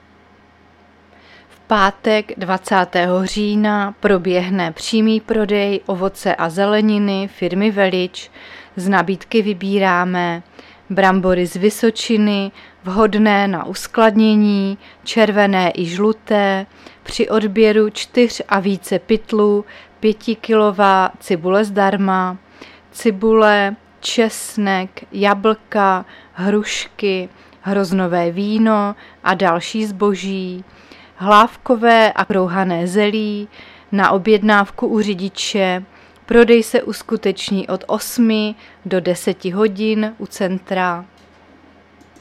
Záznam hlášení místního rozhlasu 18.10.2023
Zařazení: Rozhlas